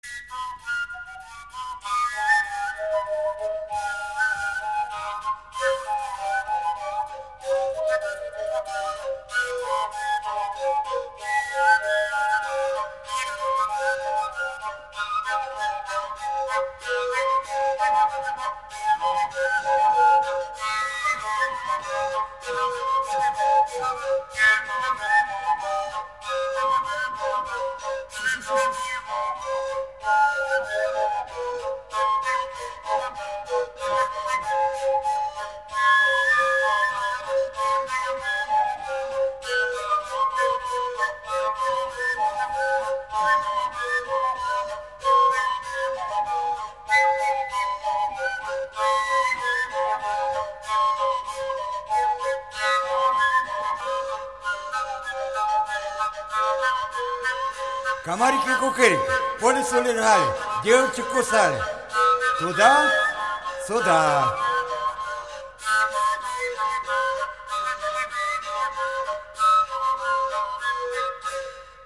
Kursk reg. folk tune